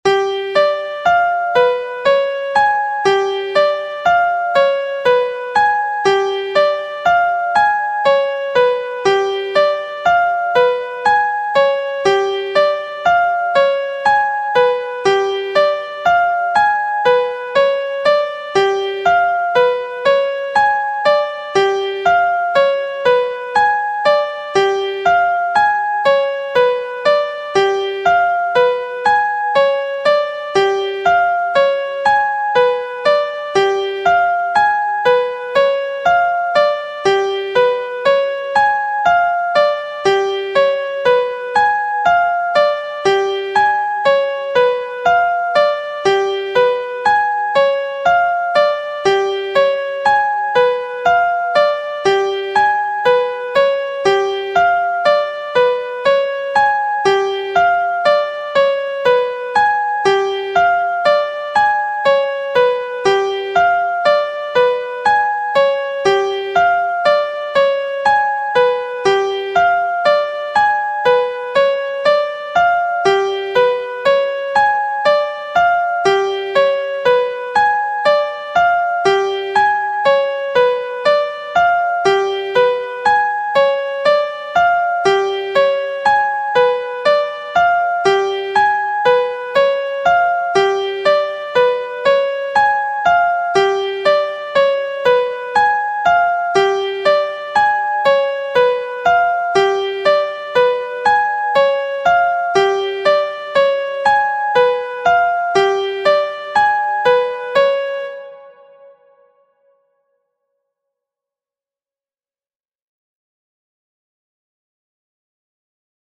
1st Inversion Exercise